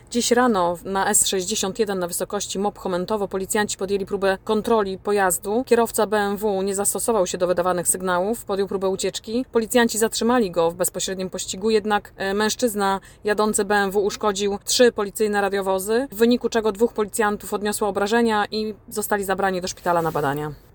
O szczegółach pościgu mówi podkomisarz